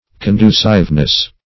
Conduciveness \Con*du"cive*ness\, n.